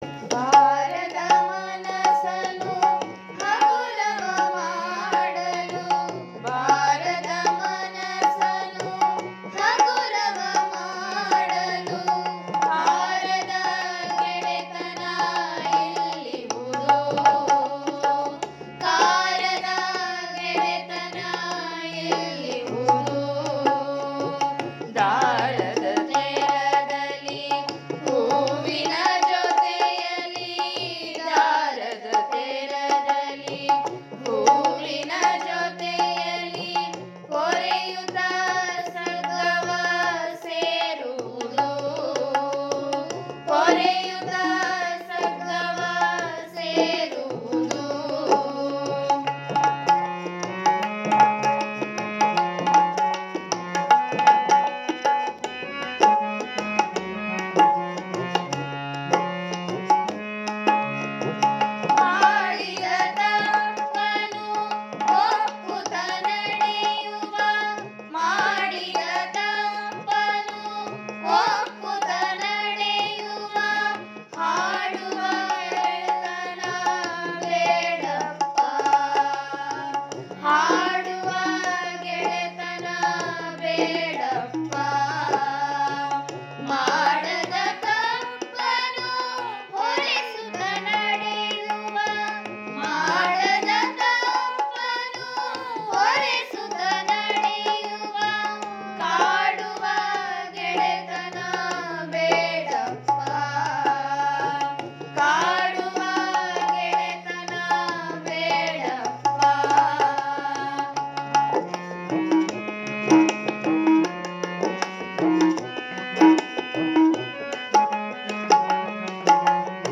ಈ ಕವಿತೆಗೆ ರಾಗ ಸಂಯೋಜಿಸಿ ವೃಂದಗಾನದಲ್ಲಿ ಸುಶ್ರಾವ್ಯವಾಗಿ ಹಾಡಿದ್ದಾರೆ.